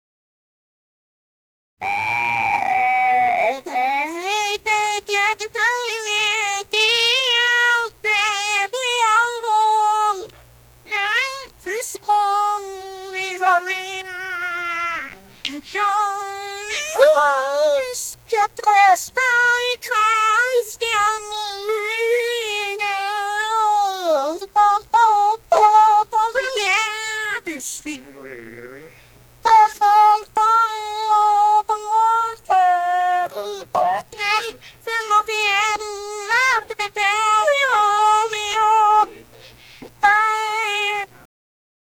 Artistic Sound Effects - Free AI Generator & Downloads